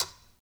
Conga-Tap1_v1_rr2_Sum.wav